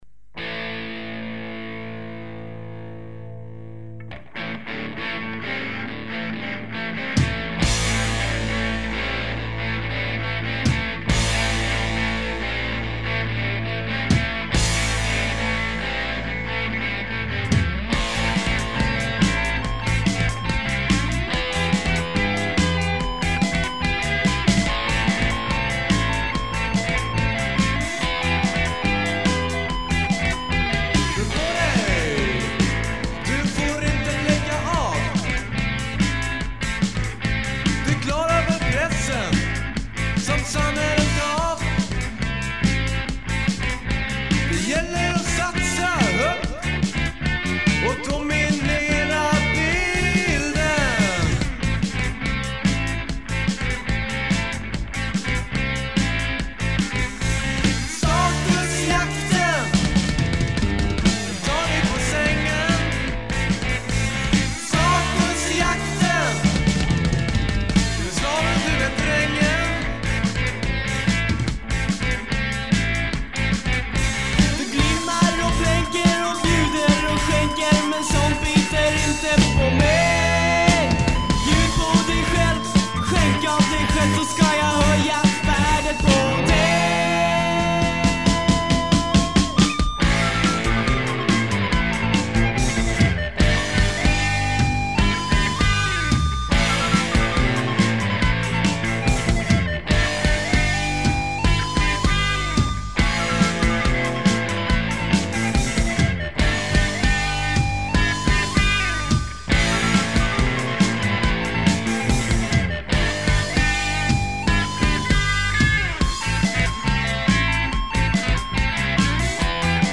Guitar
Drums
Bass
Trumpet
Trombone
Saxophone